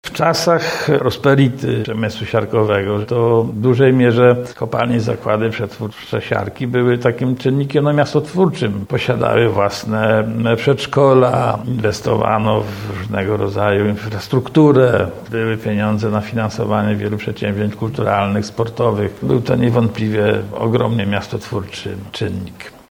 W każdy czwartek po godzinie 12.00, na antenie Radia Leliwa można wysłuchać rozmów z pracownikami przemysłu siarkowego.